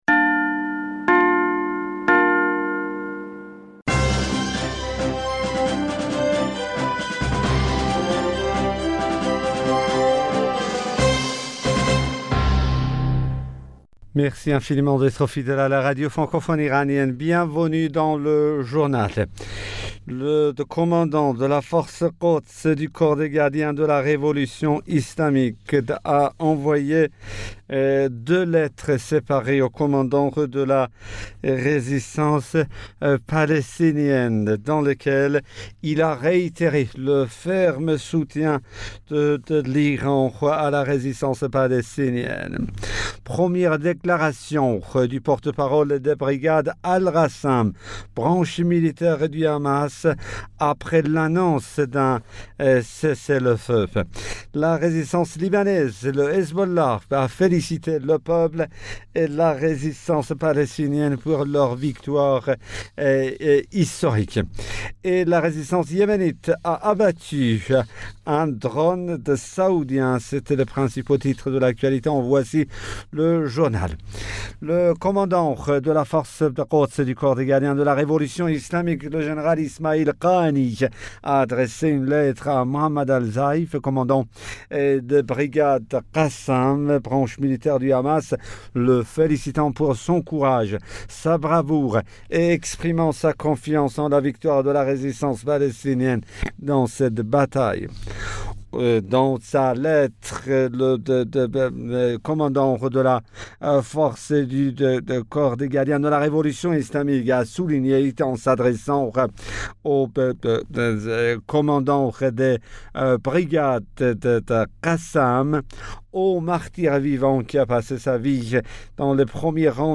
Bulletin d'information du 21 mai 2021